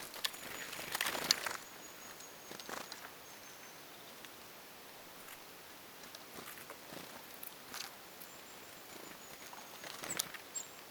tuollaista töyhtötiaisen sarja-ääntelyä
tuollaisia_toyhtotiaisen_sarja-aantelyja.mp3